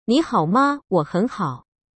步驟2：選擇中文語言，有男聲和女聲，逼真的真人發音。
Notevibes文字轉語音範例：聽聽看文字轉語音的範例：